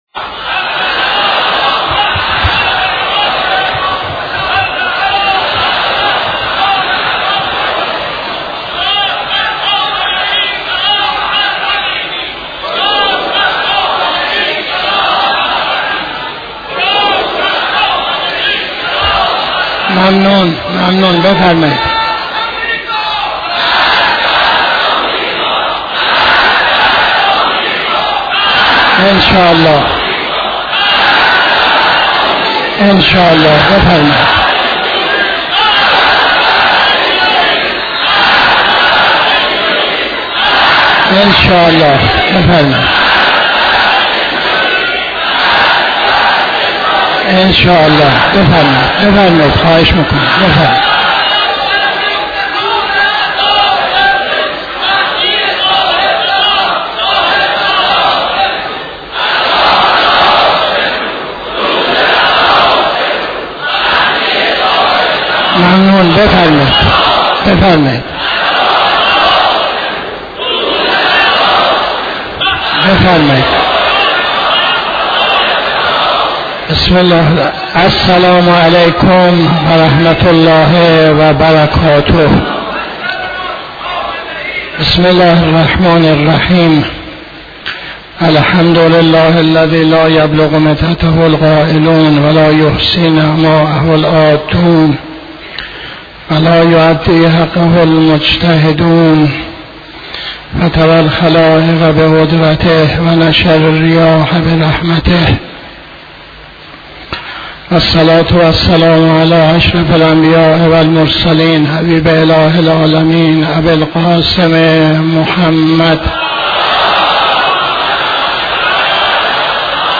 خطبه اول نماز جمعه 08-03-83